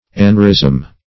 aneurism \an"eu*rism\, aneurysm